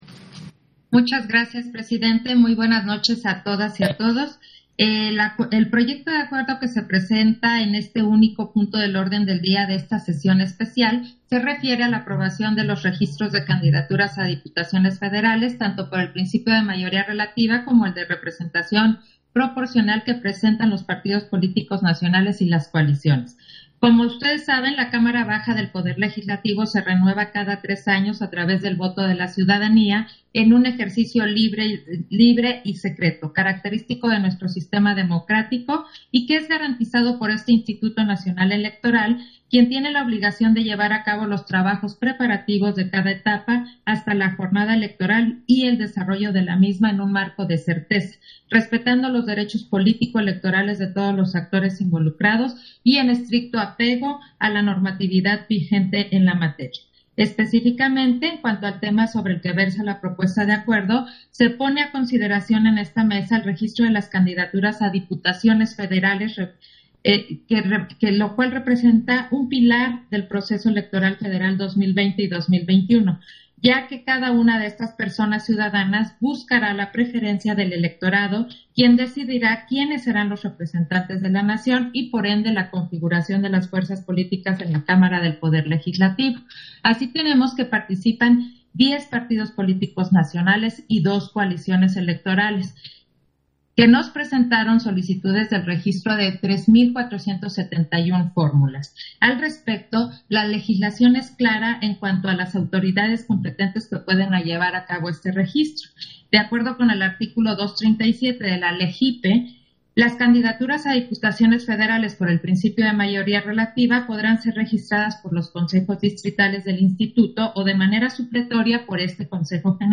Intervención de Claudia Zavala en Sesión especial, en la que se aprobaron los registros de las candidaturas a Diputaciones al Congreso de la Unión por mayoría relativa y principio de representación proporcional